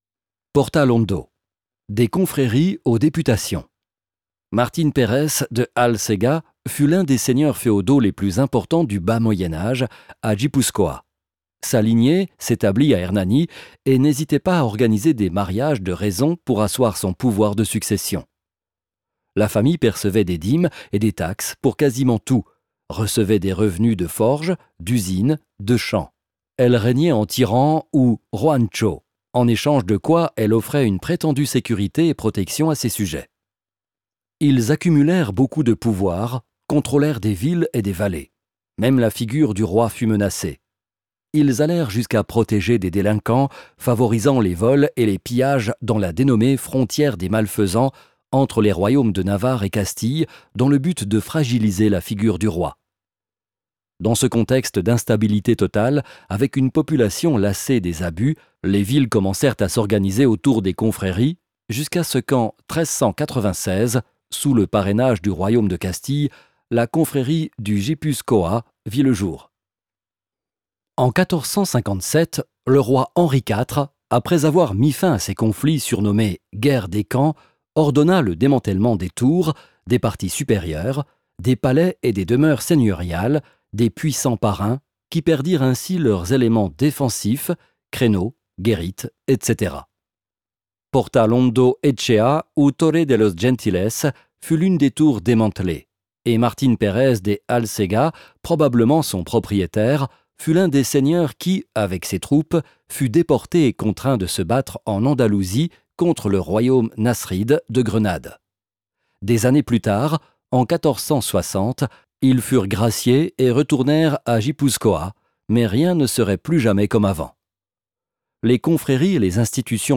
Portalondo – Hernani. Bisita guidatua